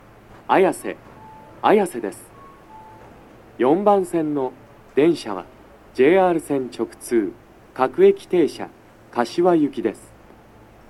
足元注意喚起放送の付帯は無いですが、0番線以外は、先発の北綾瀬行き発車ホームの案内などの駅員放送が、大変被りやすいです
男声
到着放送2